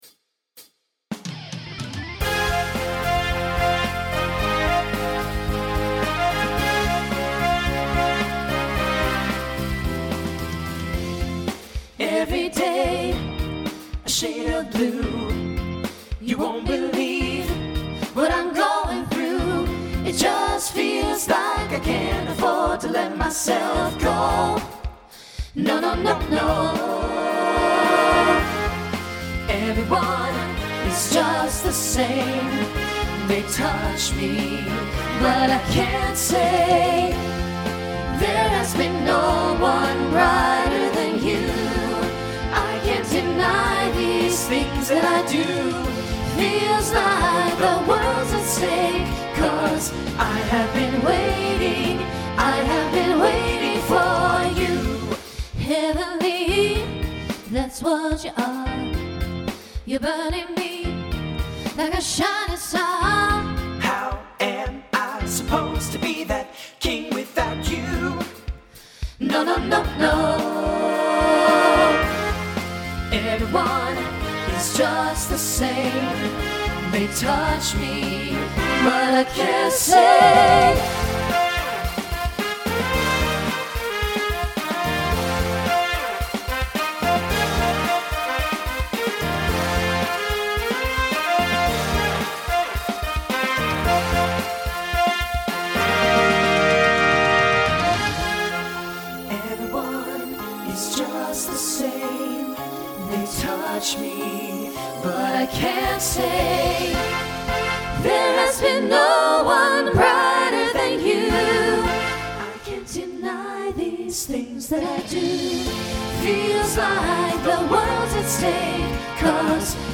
Voicing SATB Instrumental combo Genre Pop/Dance , Rock
Mid-tempo